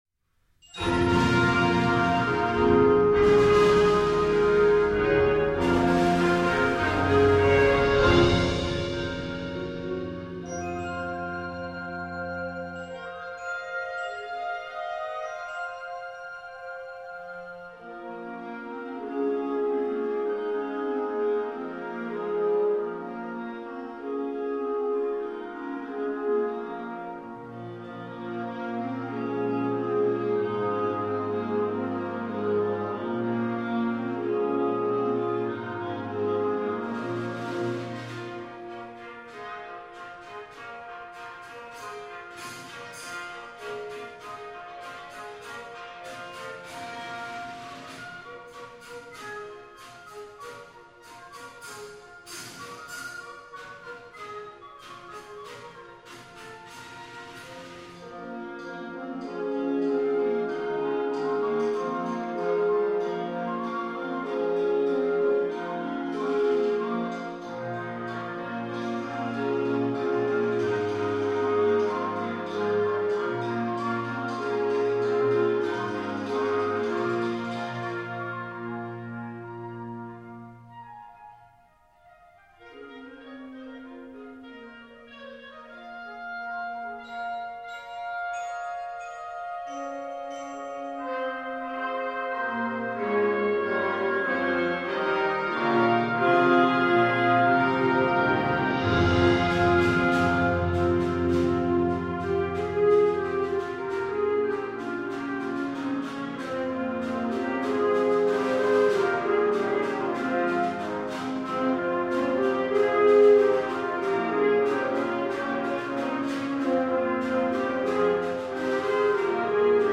Genre: Band
With hints of the medieval
Percussion 1 (snare drum, medium suspended cymbal)
Percussion 5 (vibraphone)